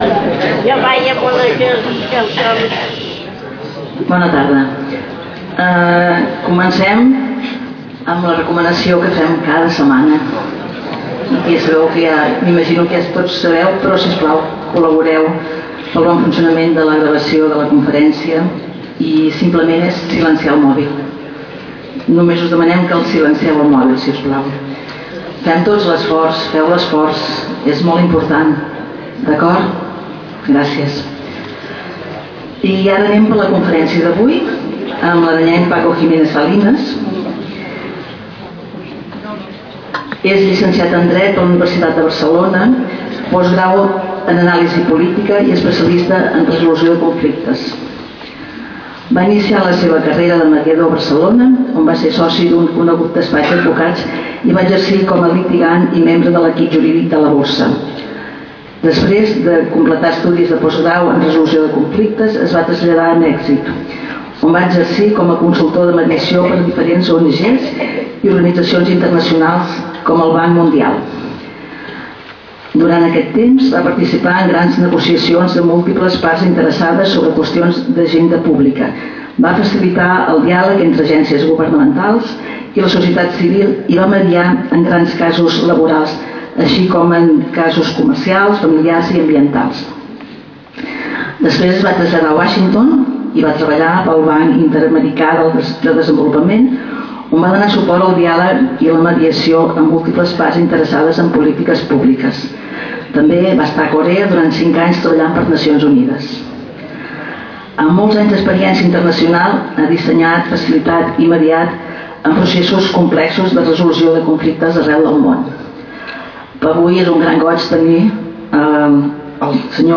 Lloc: Casal de Joventut Seràfica
Aula-28-Octubre-2025.mp3 Categoria: Conferències Notícies La mediació és una metodologia que s'aplica a tot tipus de conflicte.